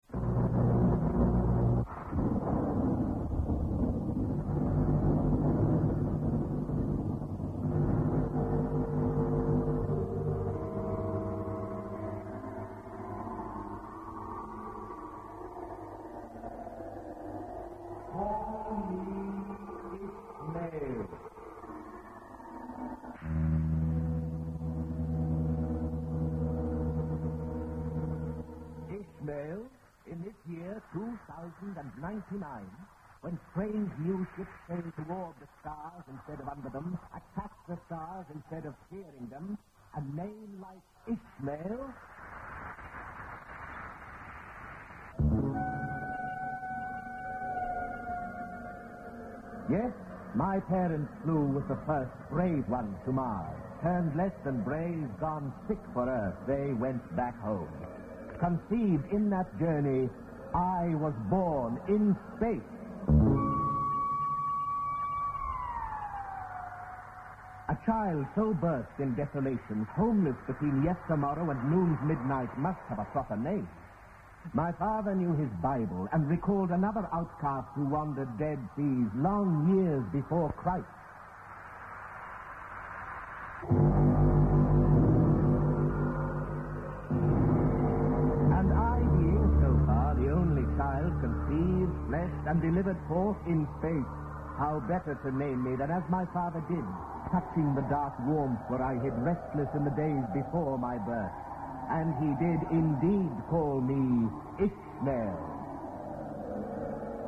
Christopher Lee (the Captain) - who was pivotal in getting the play produced by the BBC.
The play also made extensive use of sound montages built up out of classical music and historic speeches. The play was apparently recorded over an extended period of a few weeks in March and April 1968.